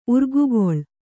♪ urgugoḷ